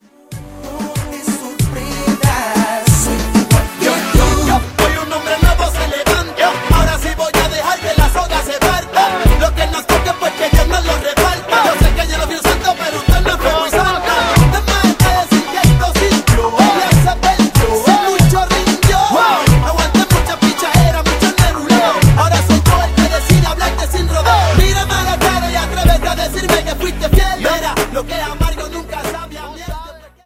Reggaeton Charts - November 2008